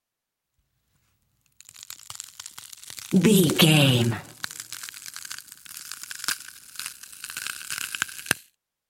Creature eating break bone
Sound Effects
scary
ominous
eerie